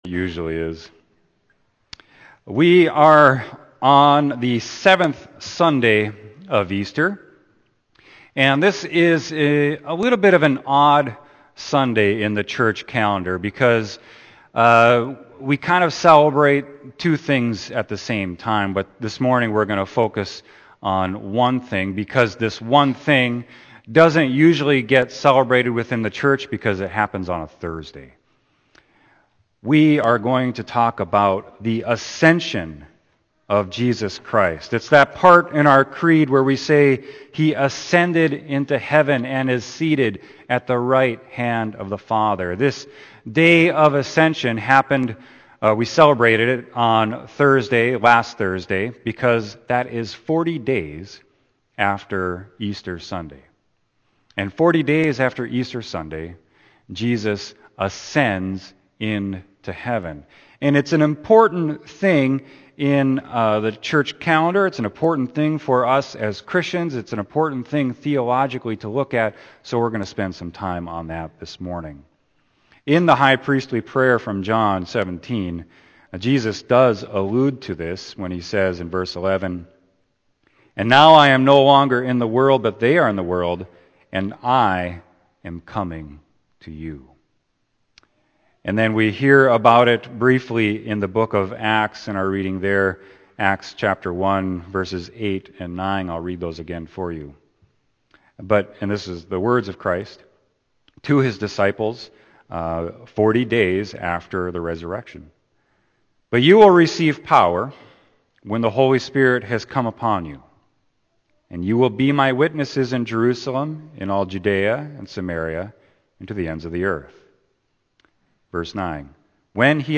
Sermon: Acts 1.6-11